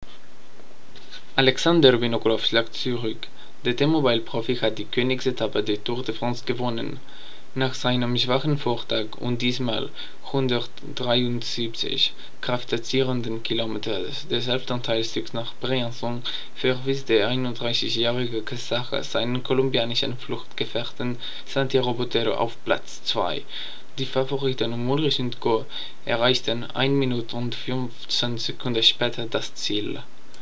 Non-native speaker
Accent: Catalan